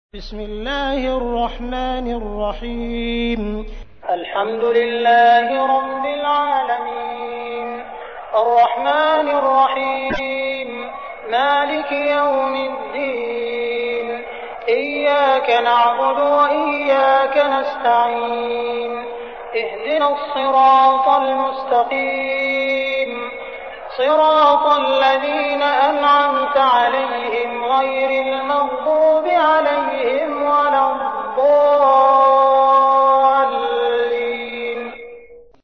تحميل : 1. سورة الفاتحة / القارئ عبد الرحمن السديس / القرآن الكريم / موقع يا حسين